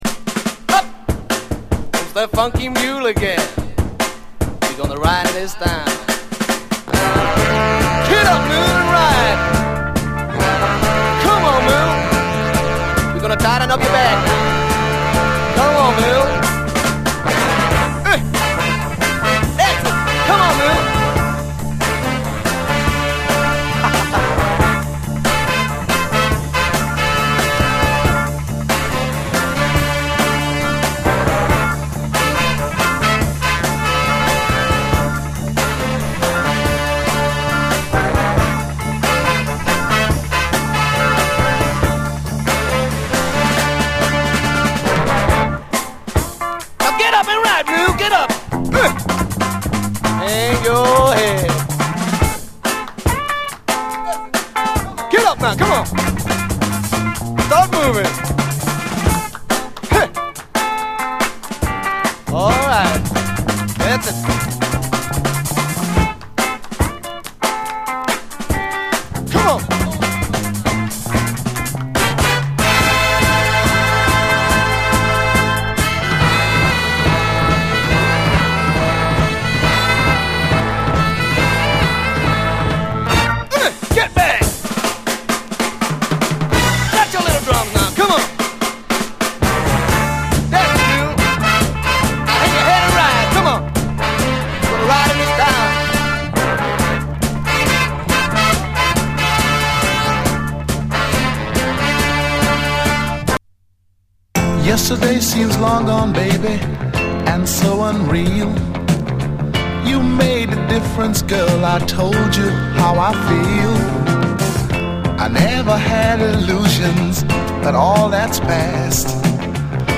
SOUL, 60's SOUL